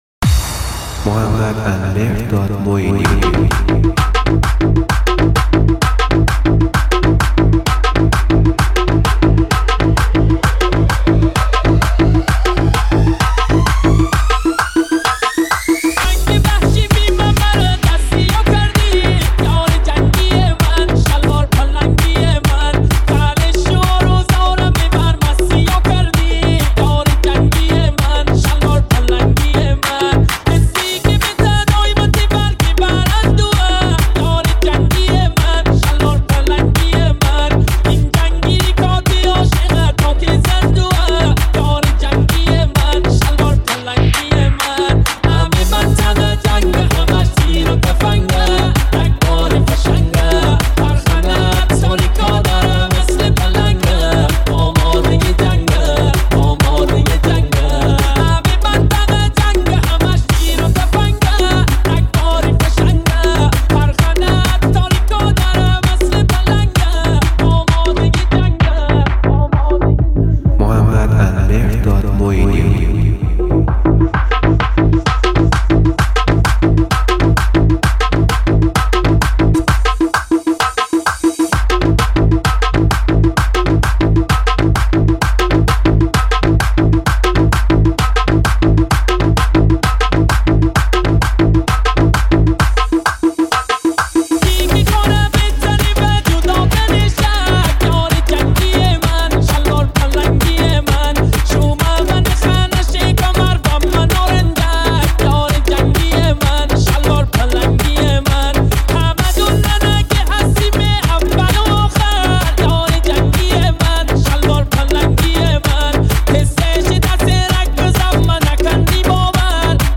ریمیکس آهنگ مازندرانی